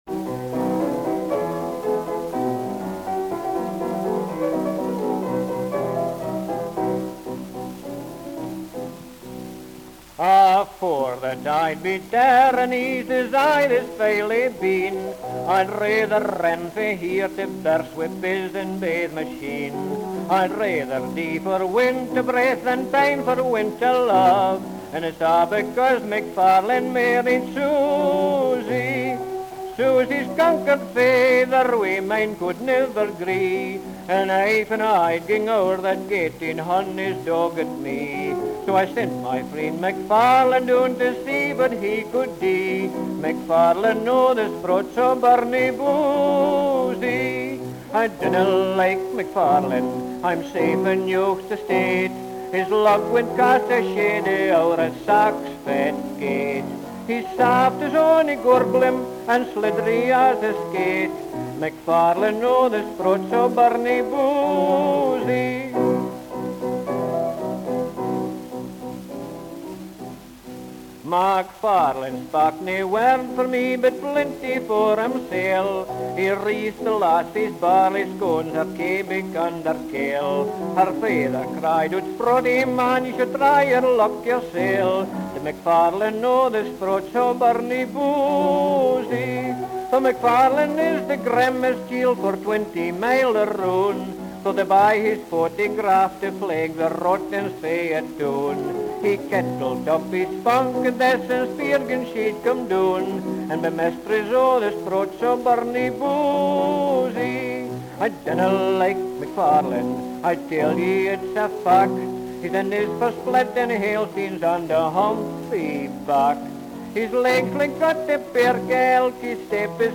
A Scottish “cornkister”
Recorded Peckham, June 1929.